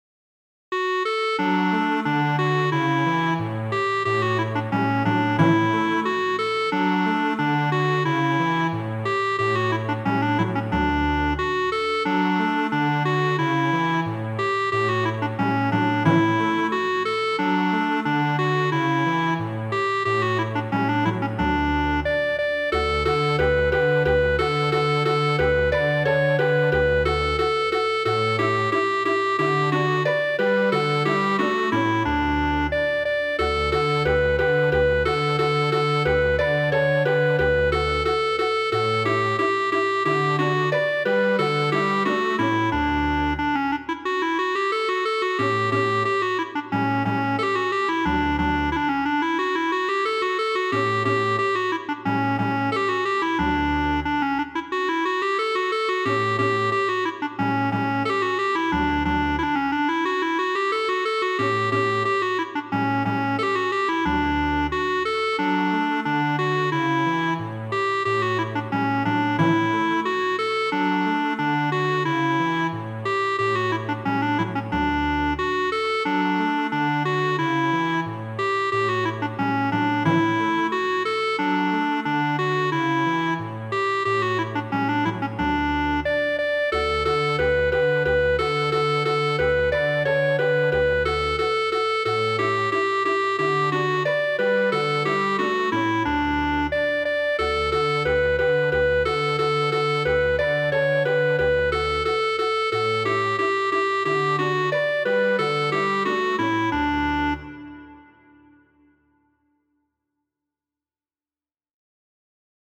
Valso, verko 23-a b de Fernando Sor, midigita de mi mem.